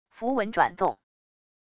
符文转动wav音频